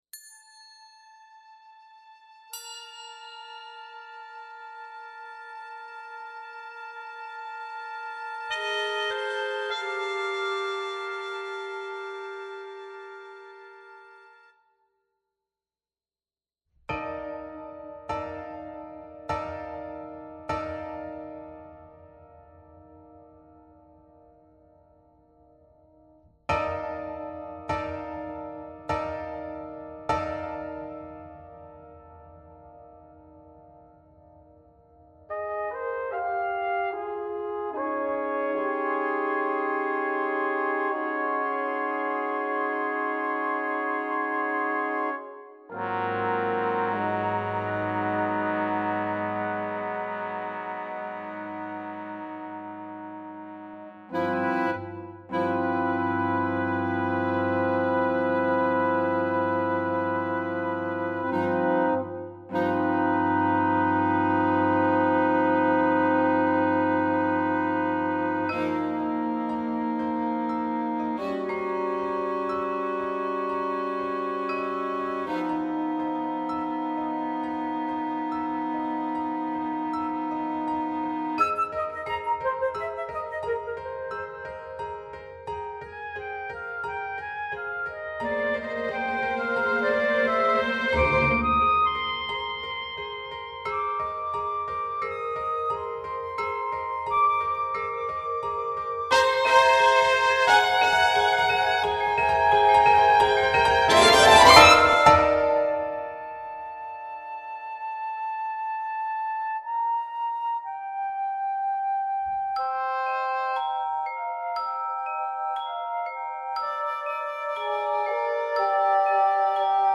Instrumental Interlude 1